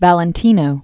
(vălĭn-tēnō), Rudolph Originally Rodolfo Guglielmi. 1895-1926.